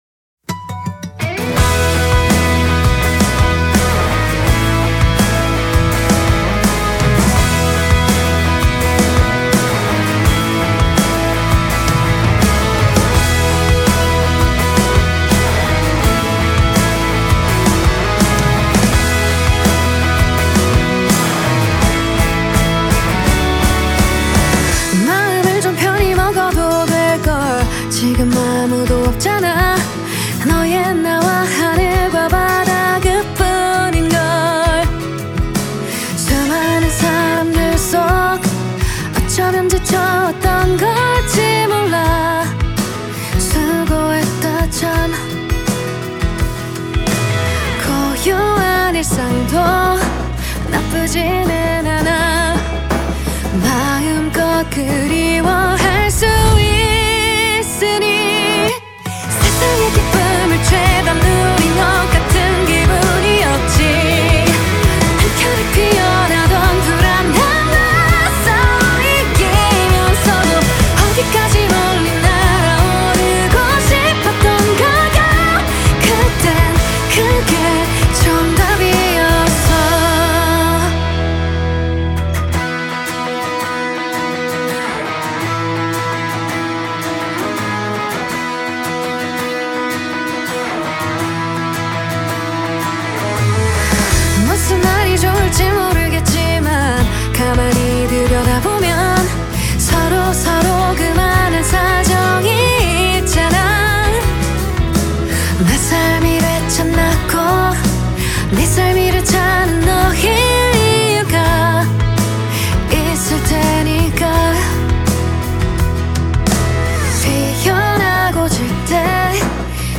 KPop
Label Pop